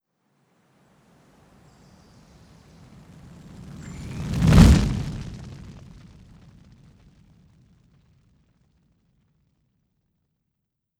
Stereo audio example. Fire sound moving across stereophonic field at 83 mph.
Wildfire-firesound-83mph.wav